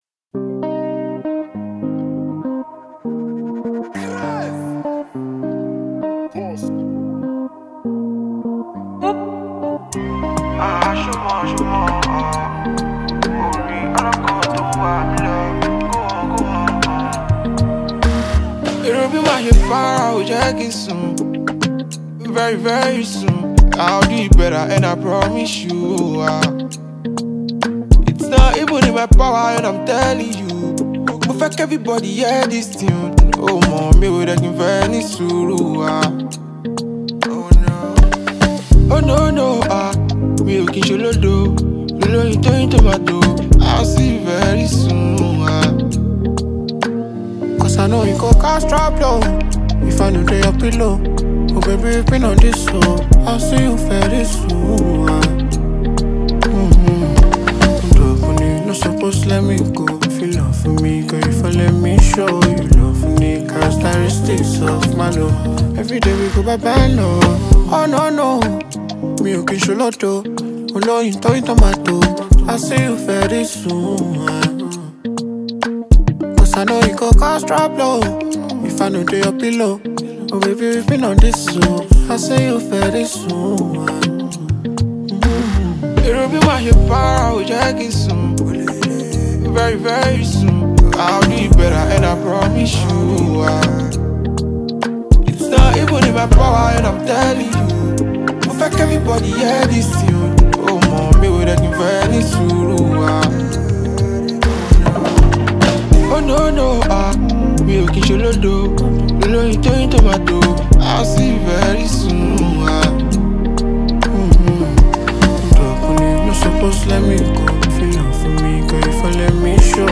Afrofusion